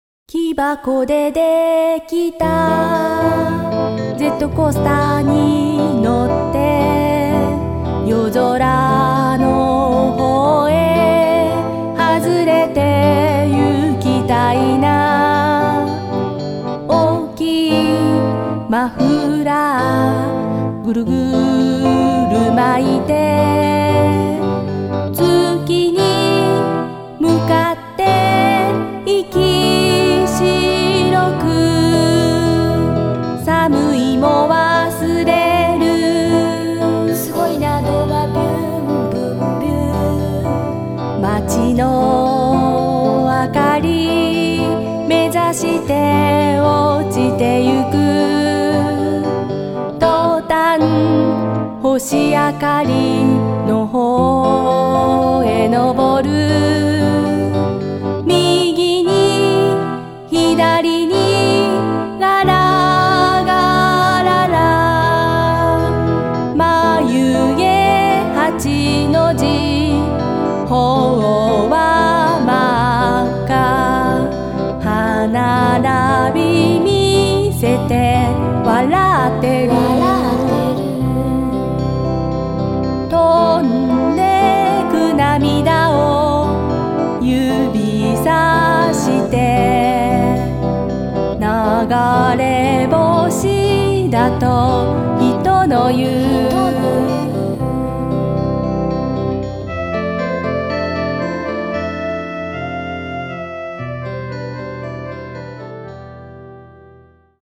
京都を中心に活動しているギター・キーボードのユニット